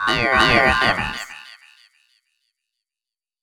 Techno / Voice
1 channel